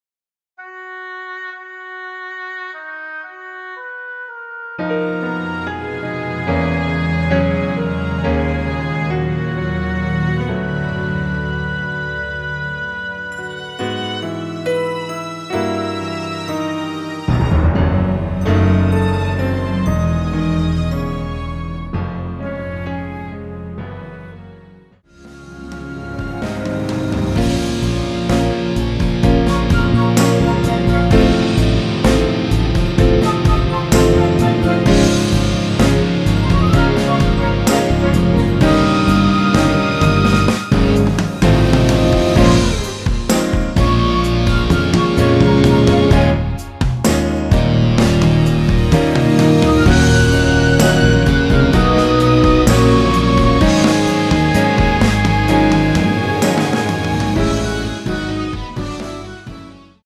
노래방에서 음정올림 내림 누른 숫자와 같습니다.
앞부분30초, 뒷부분30초씩 편집해서 올려 드리고 있습니다.
중간에 음이 끈어지고 다시 나오는 이유는
곡명 옆 (-1)은 반음 내림, (+1)은 반음 올림 입니다.